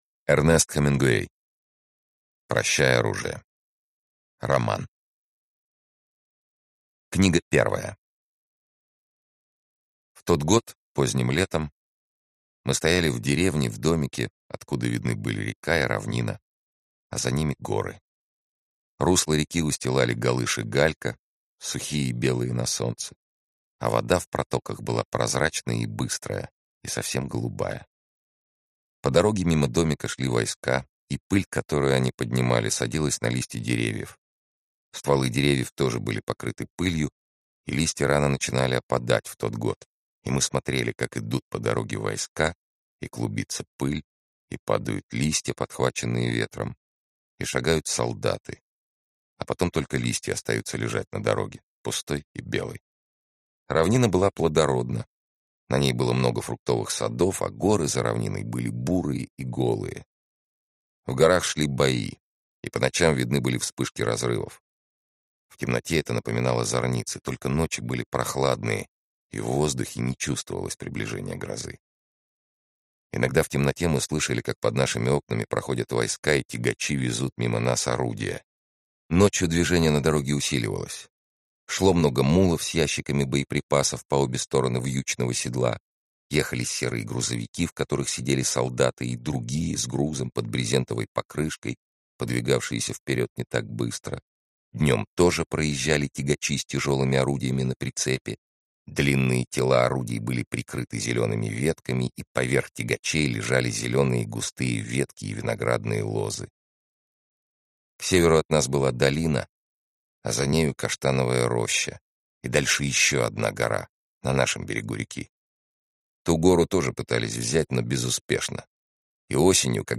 Аудиокнига Прощай, оружие!